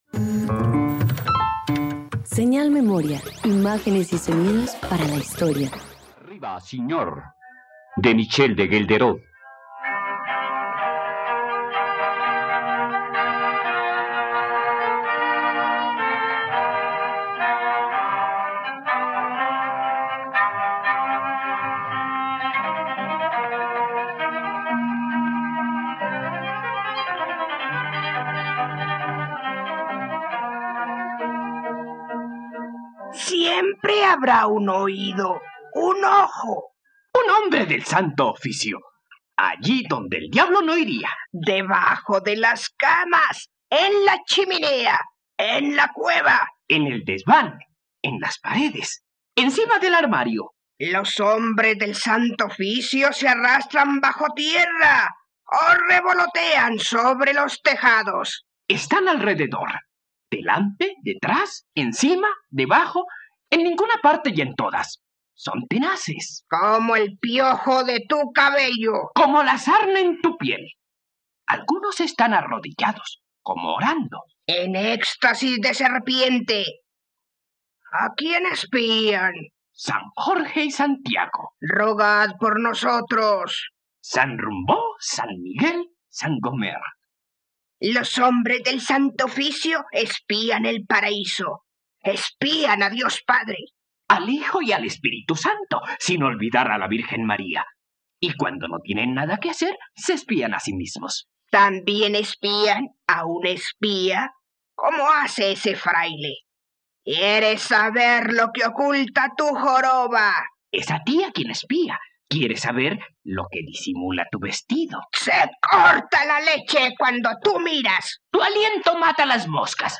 ..Radioteatro. Escucha la adaptación radiofónica de “Arriba signor” del dramaturgo Michel de Ghelderode por la plataforma streaming RTVCPlay.